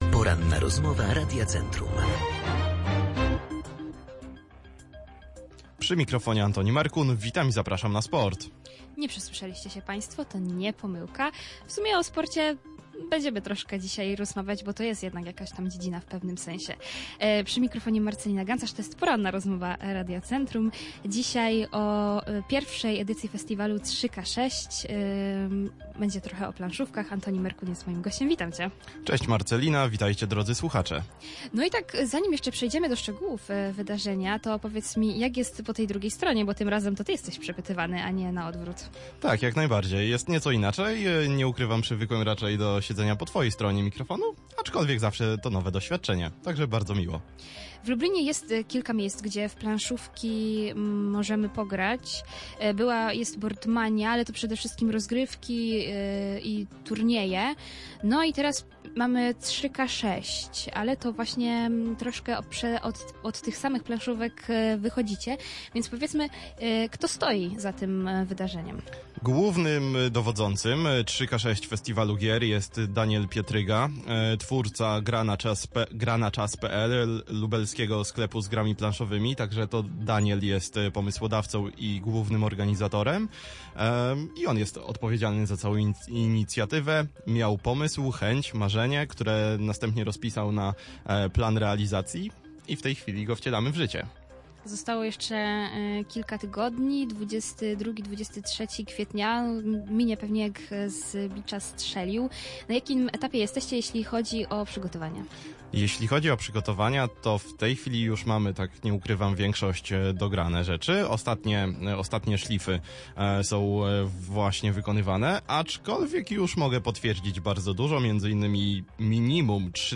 PRRC_3k6_cała rozmowa